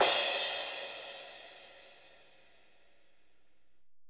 THIK CRSH.wav